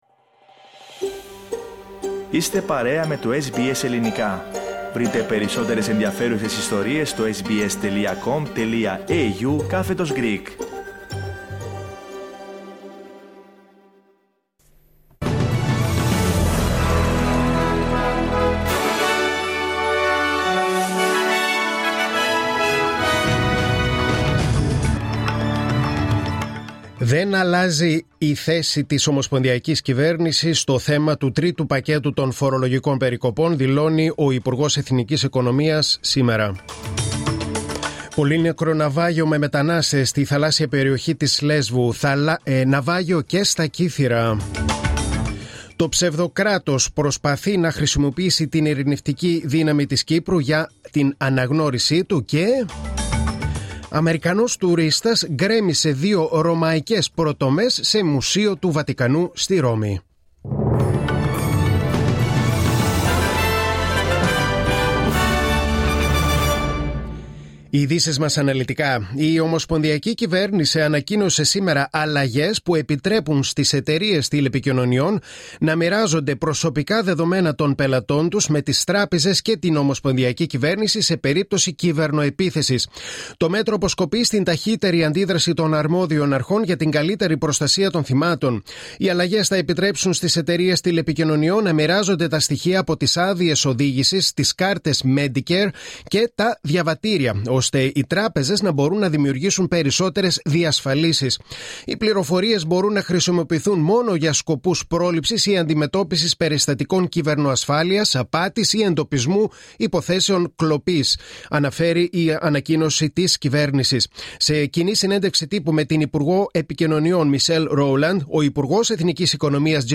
Δελτίο Ειδήσεων: Πέμπτη 6.10.2022